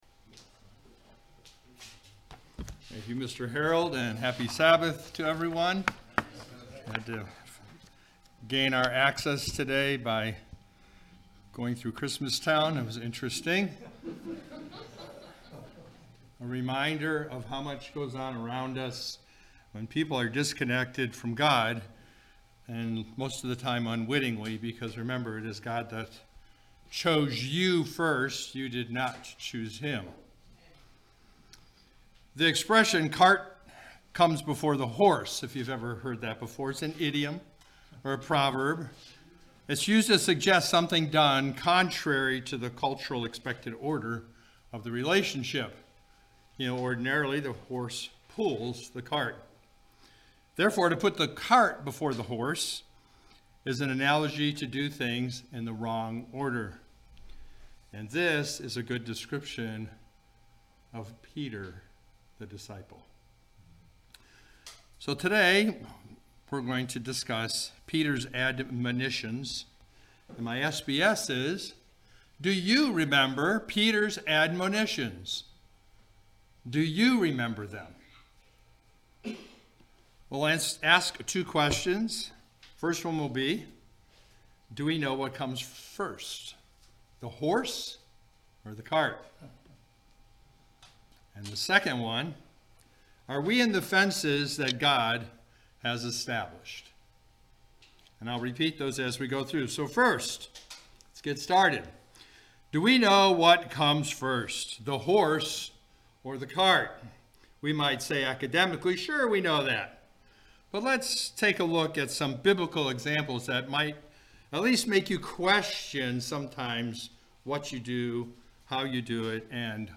Given in Ocala, FL